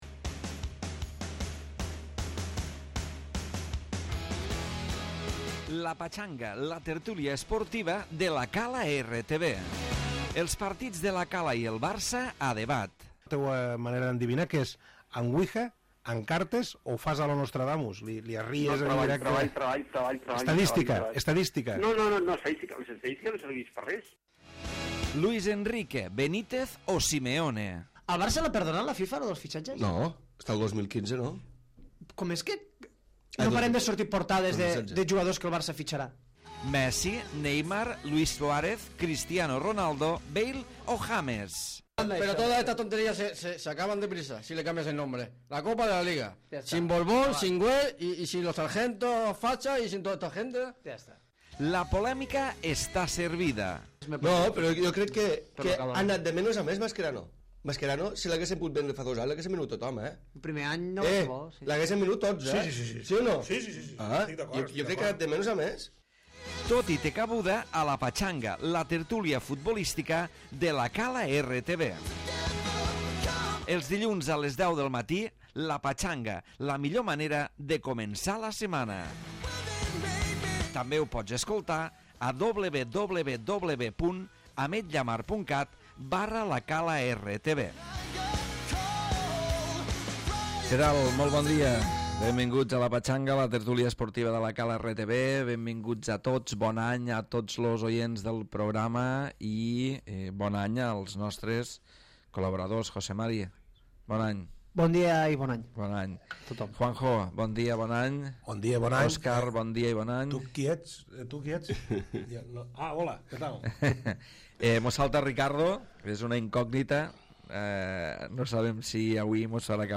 Tertúlia futbolística de la Cala RTV, avui centrada en el doble enfrontament de Copa entre el Barça i l'Espanyol, la destitució de Benítez al Madrid i moltes coses més.